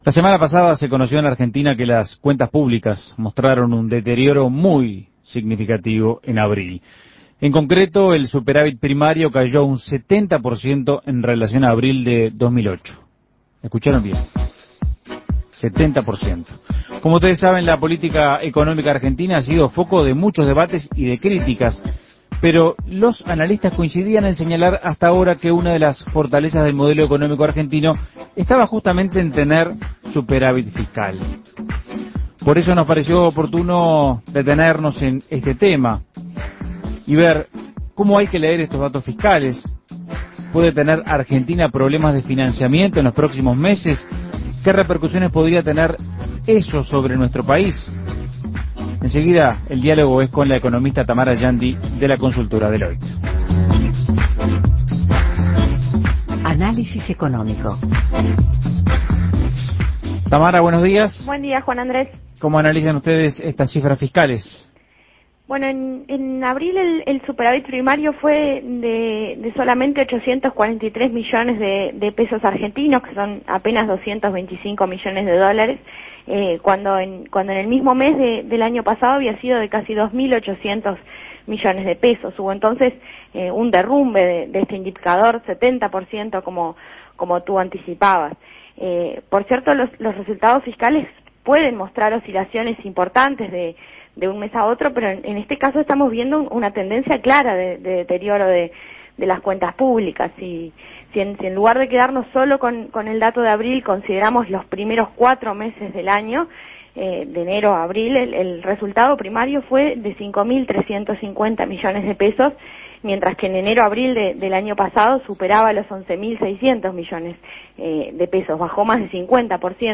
Análisis Económico ¿Cómo son las perspectivas de las cuentas públicas argentinas para este año?